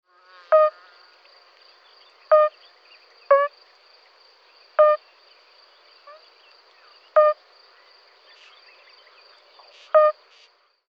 Amphibien-Aufnahmen aus aus Slowenien
Bombina bombina (ogg) (?/i) chorus